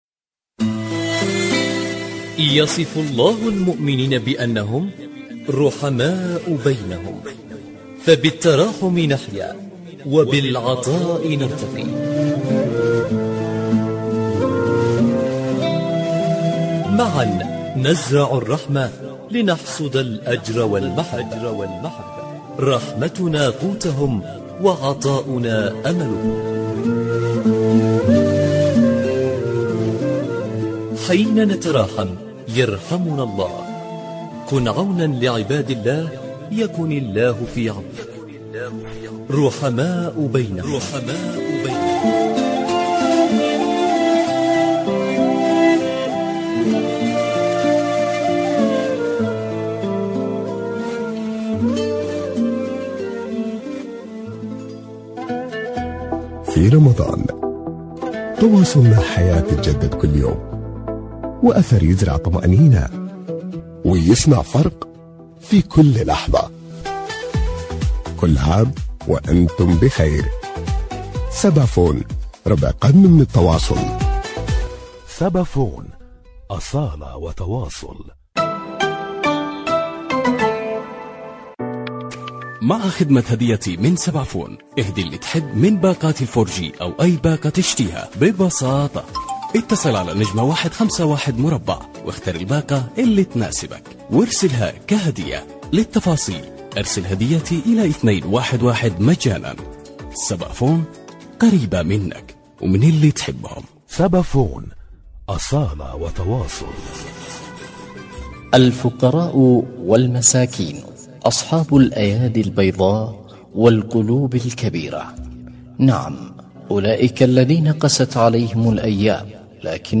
رحماء بينهم، برنامج إذاعي يأخذكم في رحلة إذاعية قصيرة ، نستكشف خلالها العديد من الحالات الإنسانية التي تحتاج الى مد يد العون في شهر الخيرات، ويسلط الضوء على حالات إنسانية.